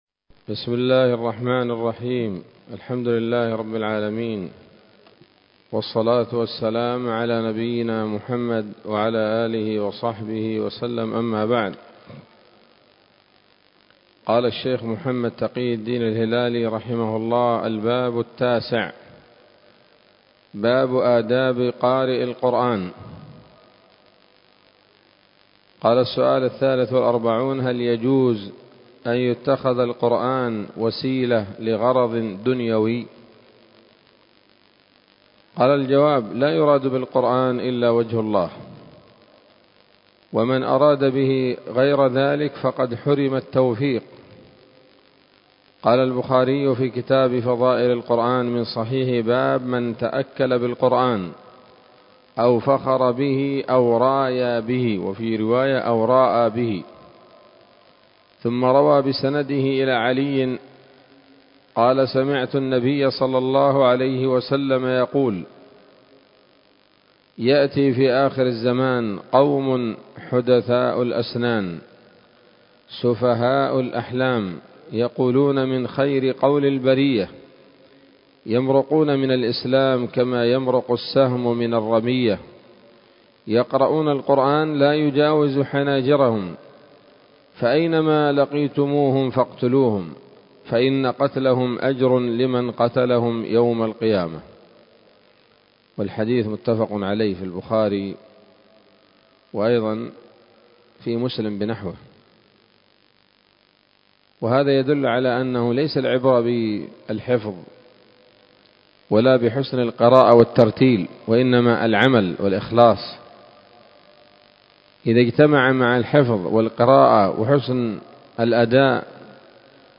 الدرس الرابع عشر من كتاب نبذة من علوم القرآن لـ محمد تقي الدين الهلالي رحمه الله